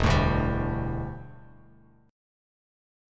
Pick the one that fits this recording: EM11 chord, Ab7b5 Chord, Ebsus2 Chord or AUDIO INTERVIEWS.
EM11 chord